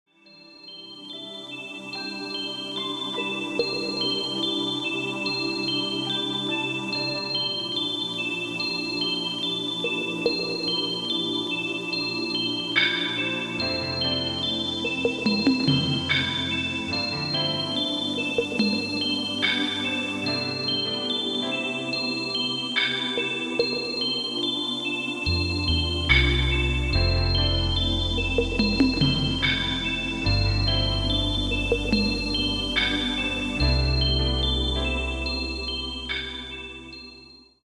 • Качество: 192, Stereo
спокойные
без слов
инструментальные
колокольчики